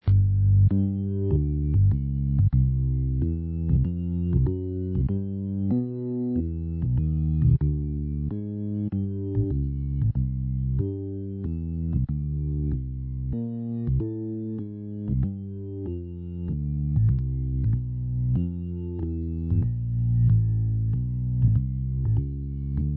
Personnellement je me fais un petit play back sur mon enregistreur généralement avec piste une piste basse, une piste accords et une piste rythme.
Petit exemple sonore sur une grille de blues en sol que je trouve très sympa, décomposée en 4 séries de 12 mesures
1) basse
2) basse + accords piano
PS : le dépot de fichier n'a pris en compte qu'une partie de mon extrait audio (qui fait au total 680 ko)